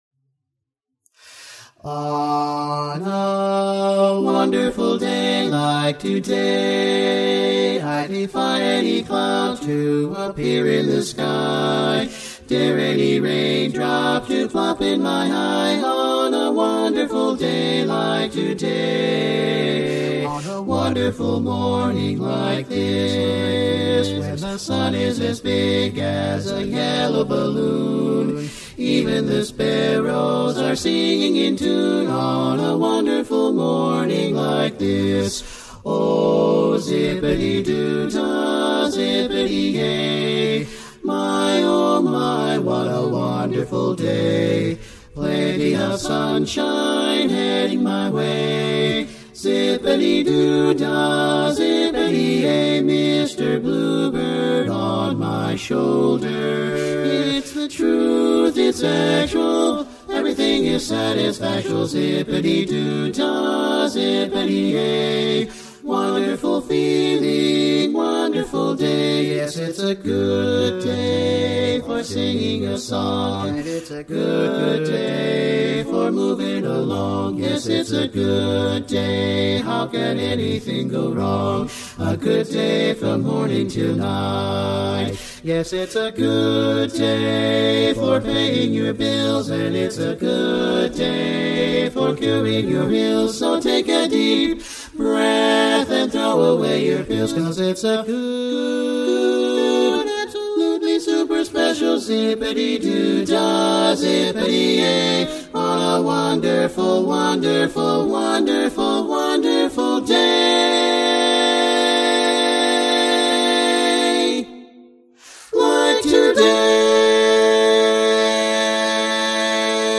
Harmony Rechoired (chorus)
Up-tempo
F Major